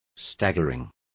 Complete with pronunciation of the translation of staggering.